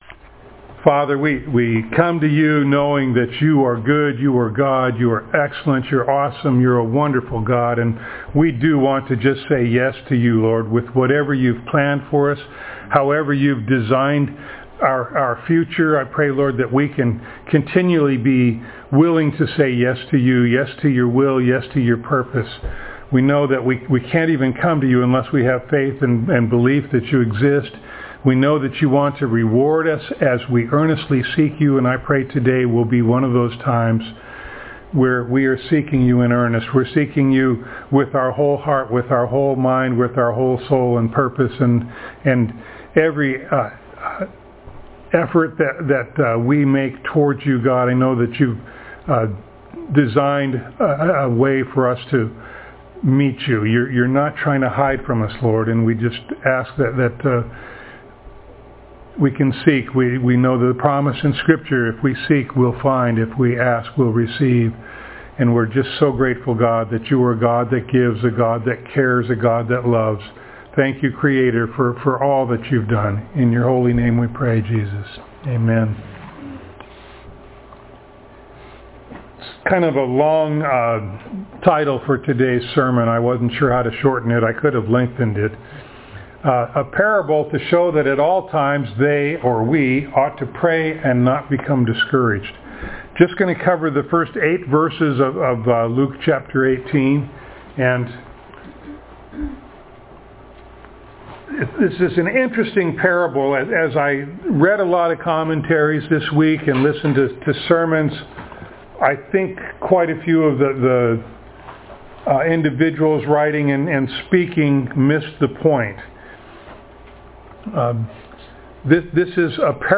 Luke Passage: Luke 18:1-8 Service Type: Sunday Morning Download Files Notes « Cleansed